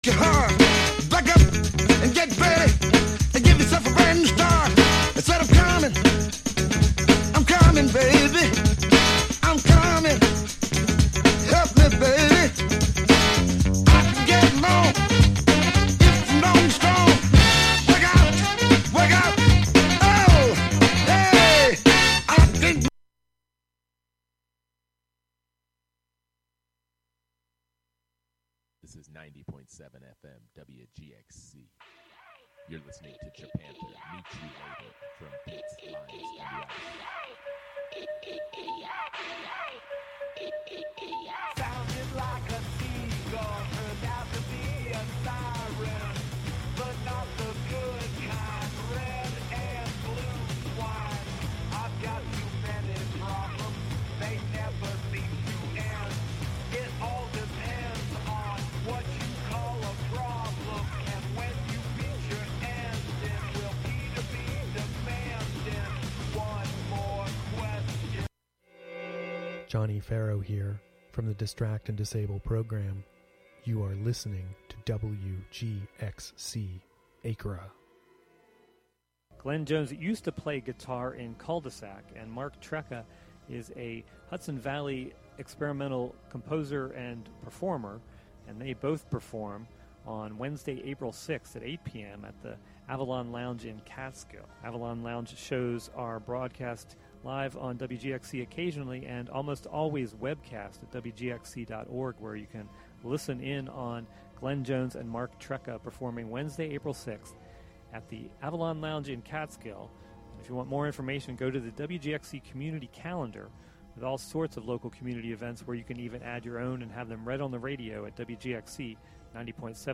A radio series of proprioceptive exercises, interviews about practices of communication, and archival sound. A routine for warming up our means of communication. Presented monthly as a combination of live and prerecorded sessions.